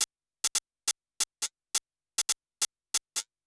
SHORT SHAK-L.wav